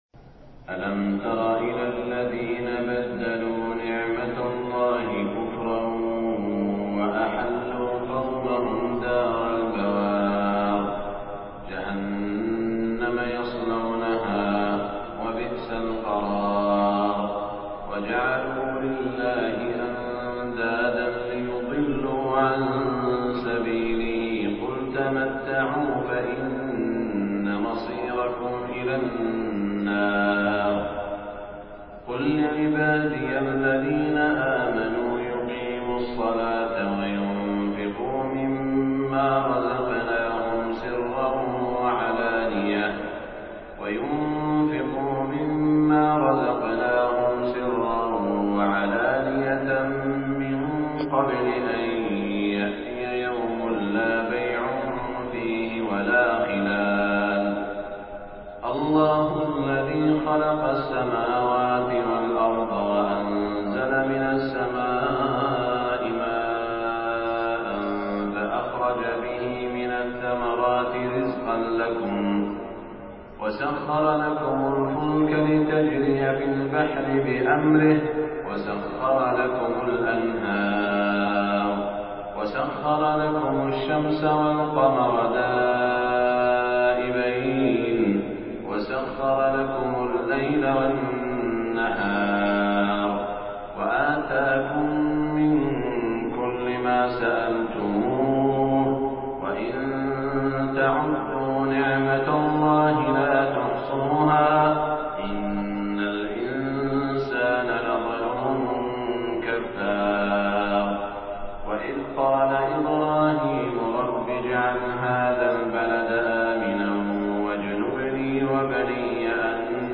صلاة الفجر 11 شوال 1427هـ من سورة إبراهيم > 1427 🕋 > الفروض - تلاوات الحرمين